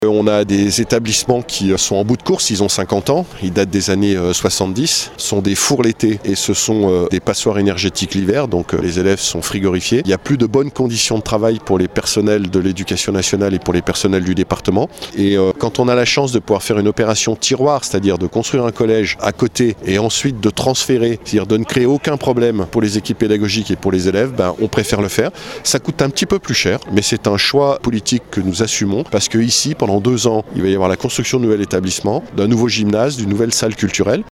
Une reconstruction qui était nécessaire comme le confirme Martial Saddier, le Président du Conseil départemental de Haute-Savoie (collectivité de référence pour les collèges) :